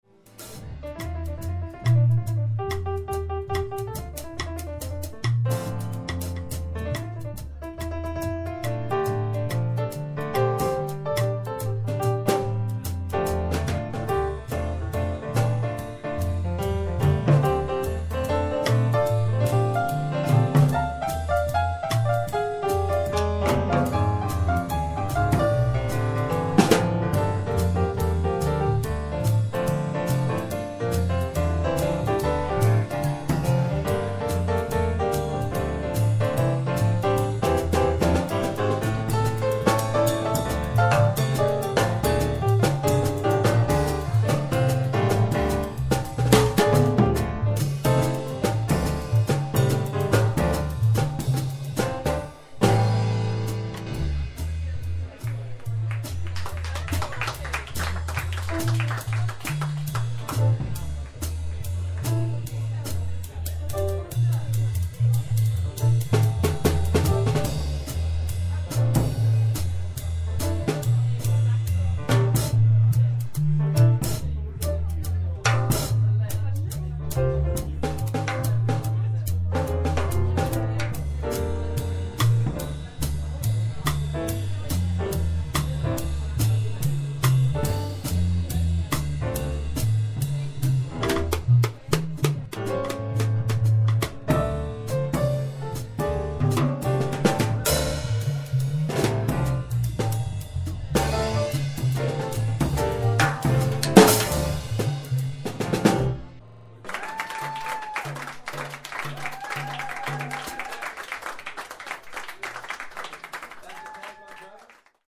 folk, jazz and roots music with guitar and violin.